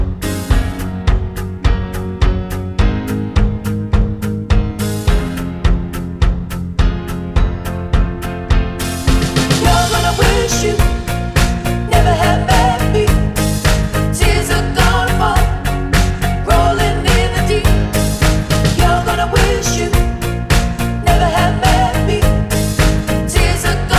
Two Semitones Down Pop (2010s) 3:46 Buy £1.50